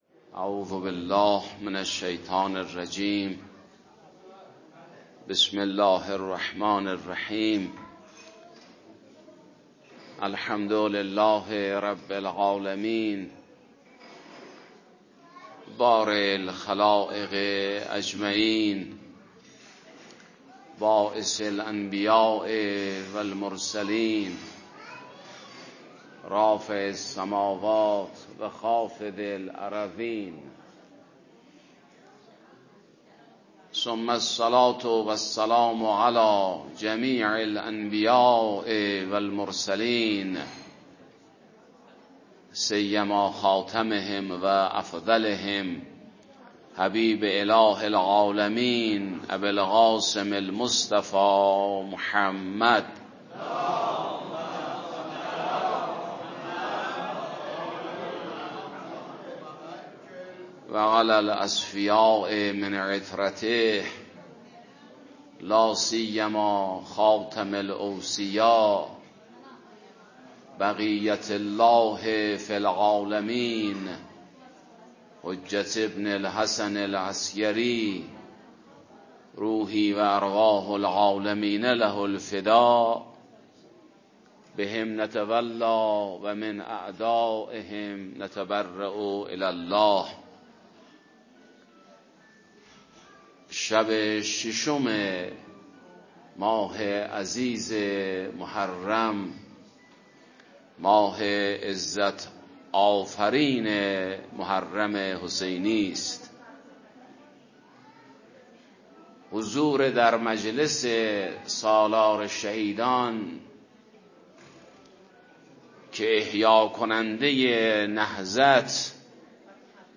در شب ششم ماه محرم، مجلس عزاداری حضرت ابی‌عبدالله الحسین علیه‌السلام با حضور علما، فضلای حوزوی و عموم مردم مؤمن و دلداده اهل‌بیت علیهم‌السلام برگزار شد.